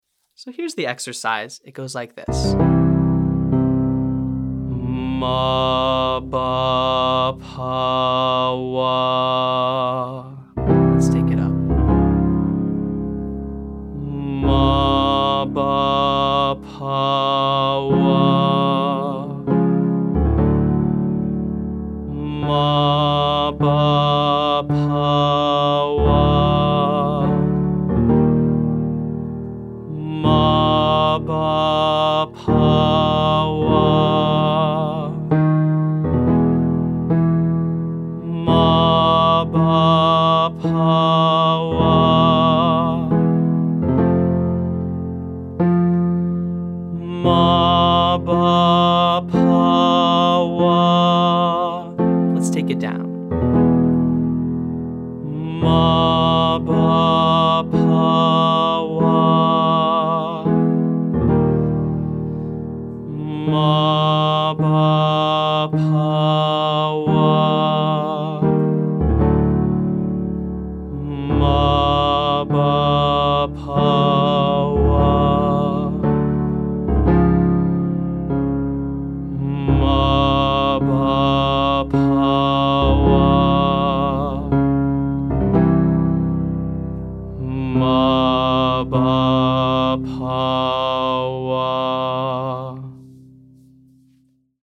• Mah, Bah, Pah, Wah